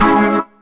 B3 CMIN 2.wav